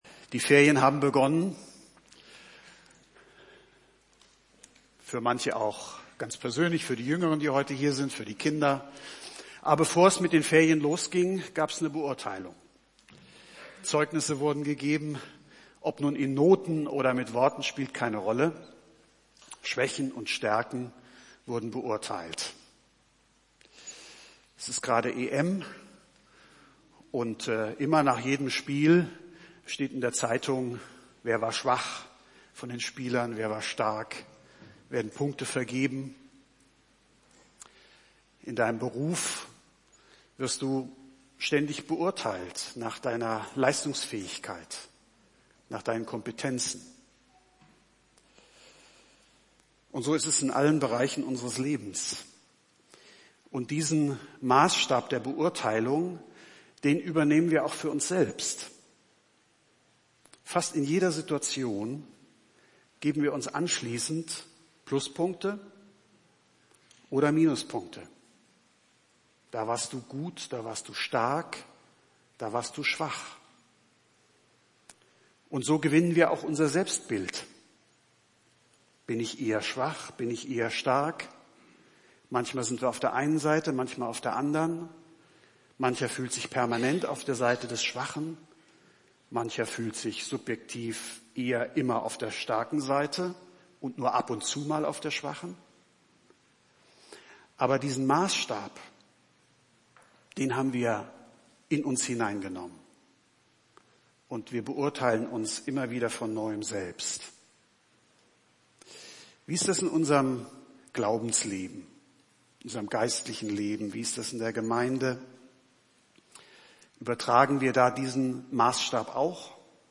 Predigttext: 2. Korinther 12, 1-10